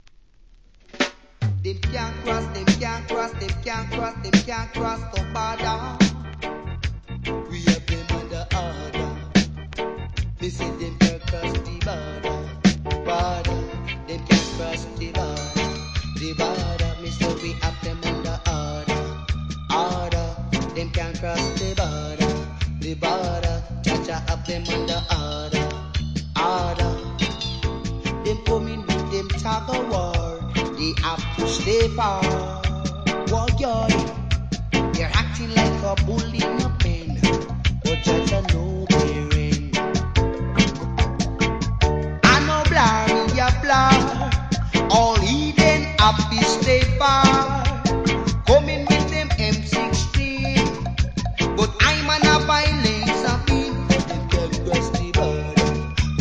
REGGAE
各曲DUB接続します。